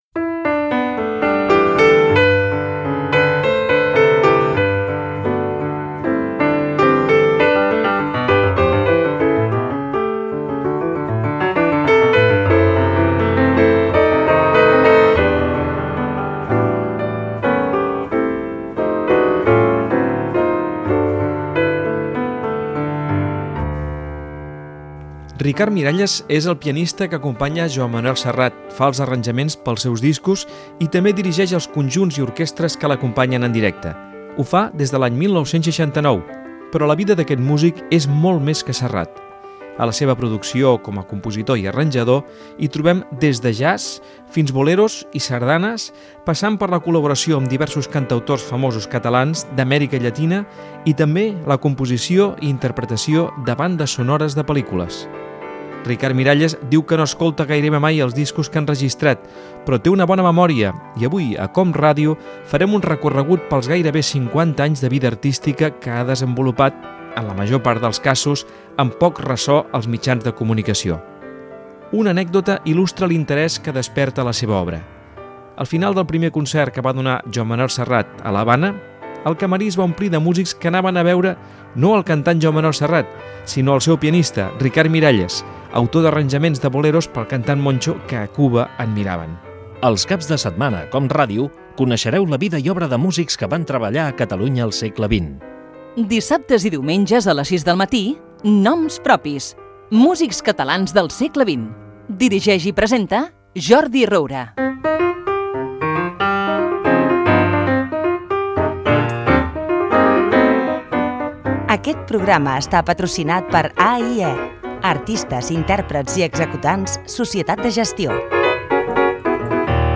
Fragment extret de l'arxiu sonor de COM Ràdio.